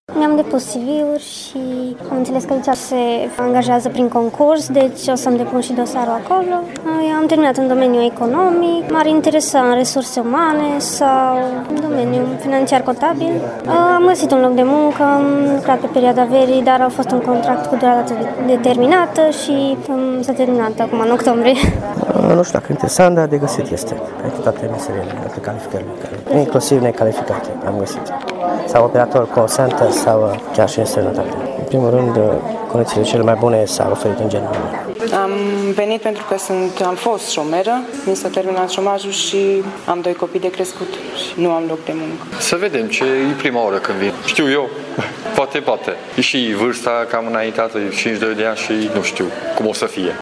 Târgul de Cariere a adus astăzi, la Teatrul Național din Târgu Mureș, aproximativ 25 de companii care au oferit locuri de muncă pentru toată lumea.
Candidații care au căutat astăzi un loc de muncă au intrat la Teatru pe baza înscrierilor în aplicația dedicată târgului.